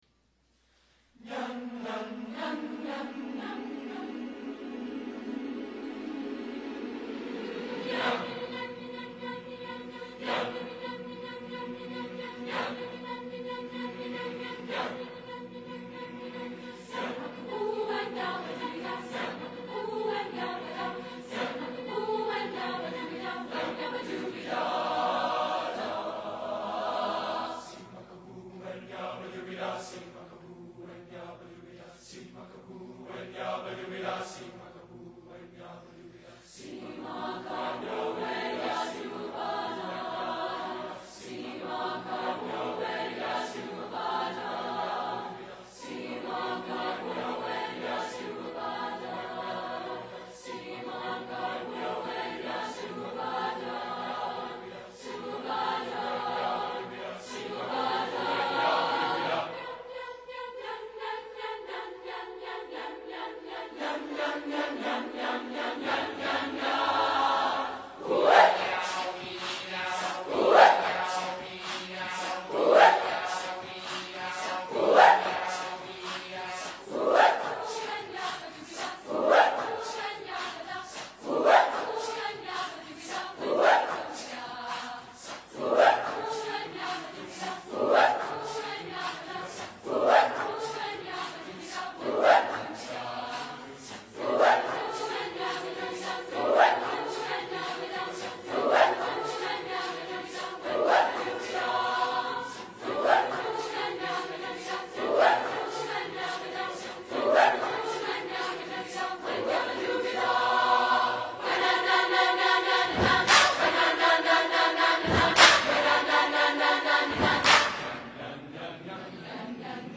Performance